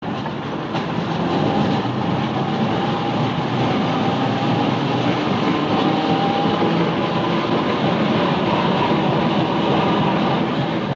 trainmove.ogg